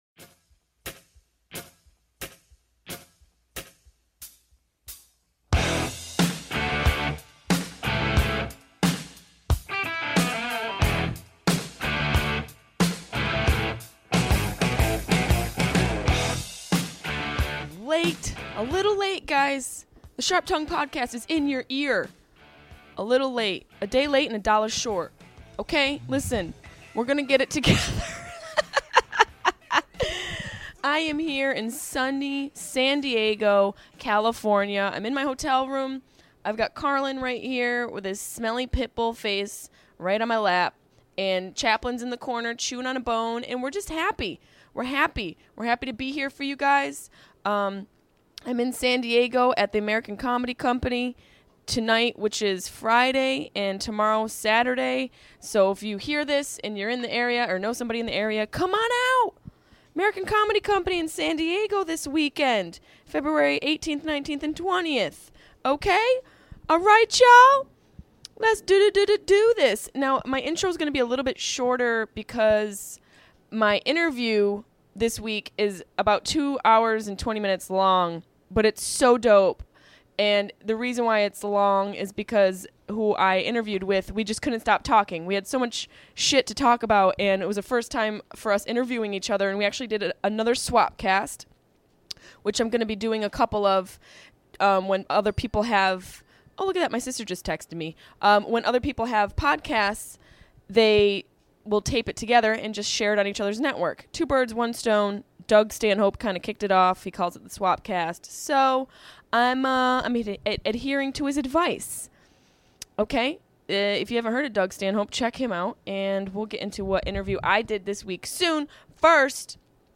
I went to Bert's mancave to talk about comedy, cosby and chickens.